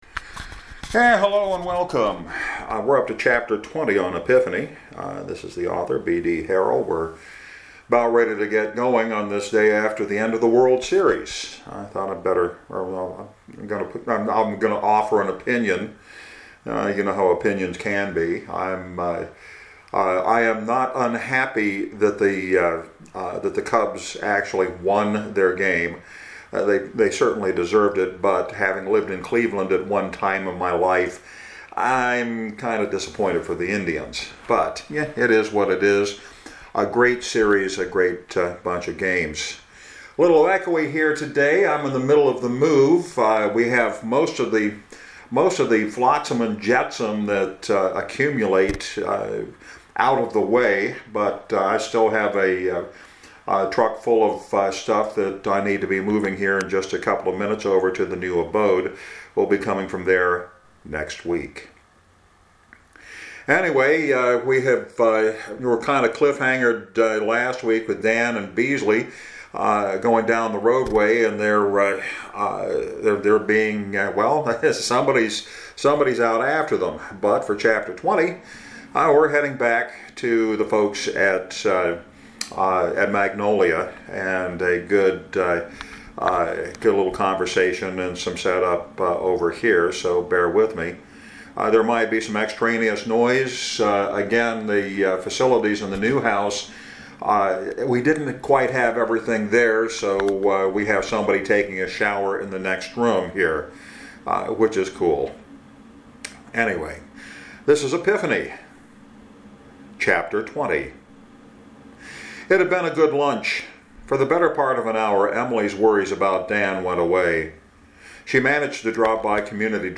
In the middle of the move, I have found a little bit of time to read the next chapter in Epiphany. This week, Debbie Wallace has a problem which she needs help solving.